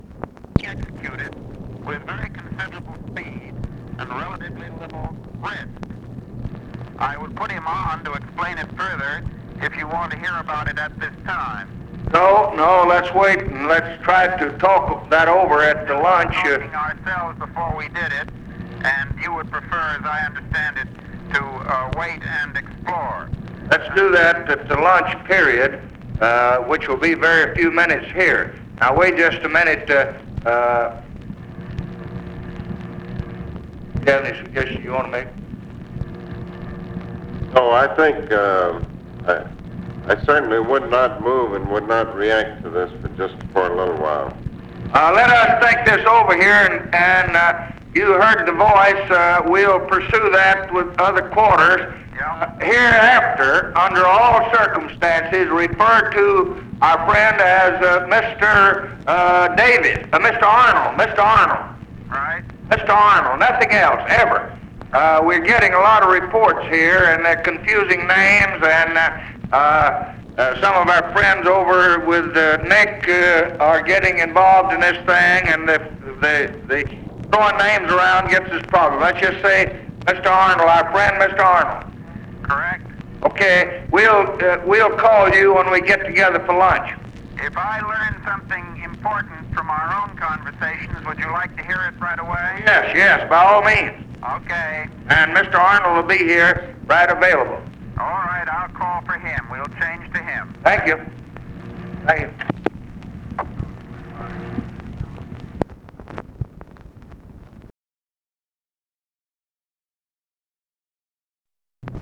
Conversation with MCGEORGE BUNDY and ABE FORTAS, May 18, 1965
Secret White House Tapes